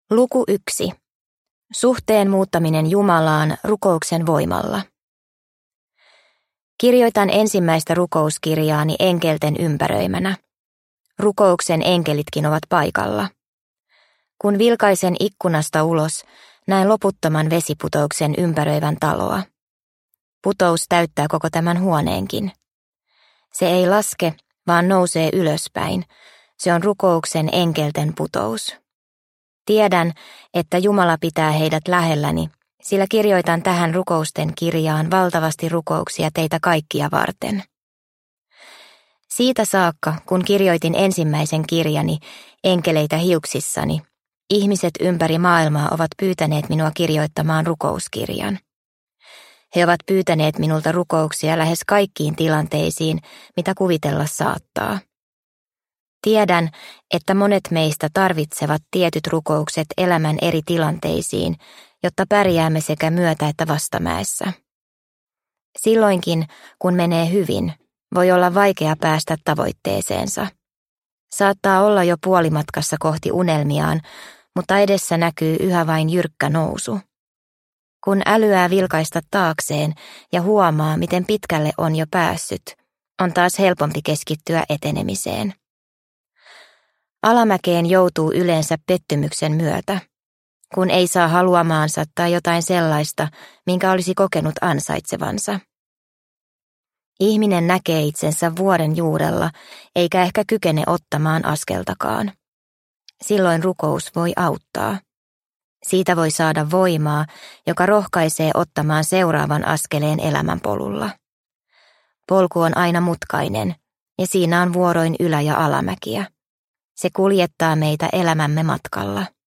Rukouksia sydämestä – Ljudbok – Laddas ner